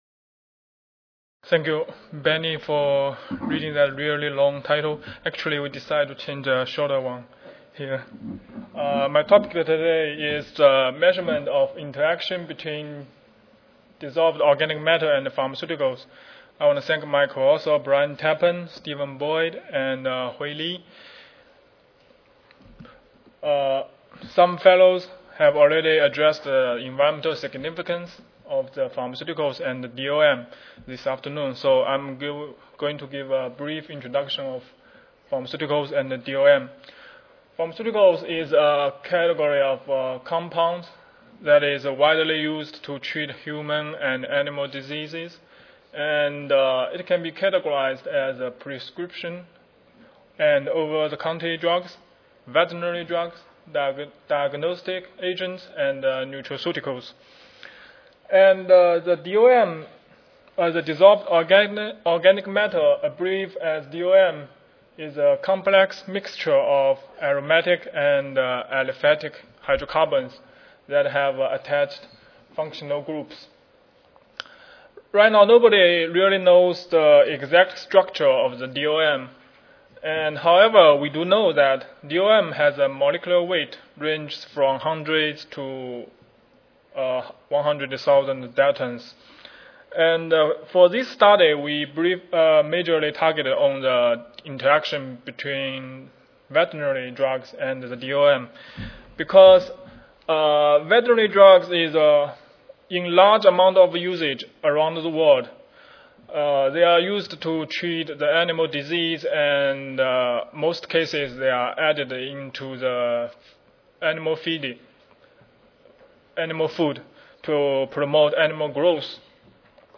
MI Recorded Presentation Audio File An innovative method was developed to determine interactions between pharmaceuticals and dissolved organic matter (DOM) using solid phase extraction.